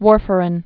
(wôrfər-ĭn)